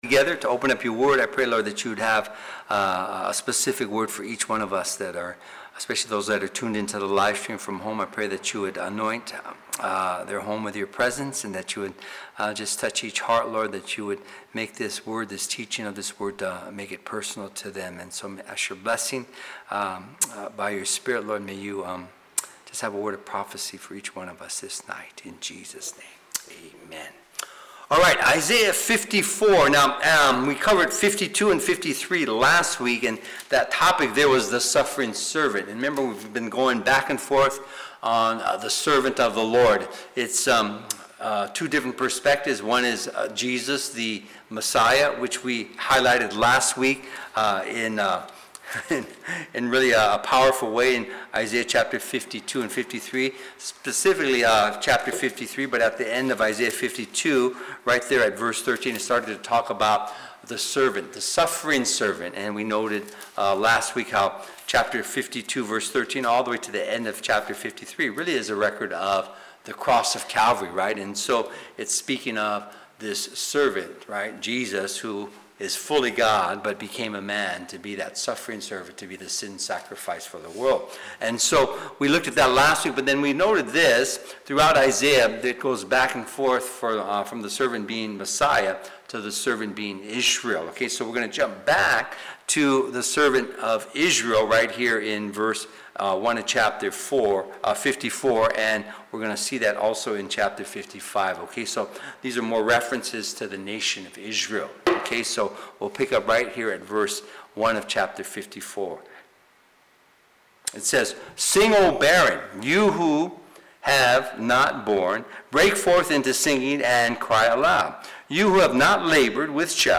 Sermons | Calvary Chapel Echo Park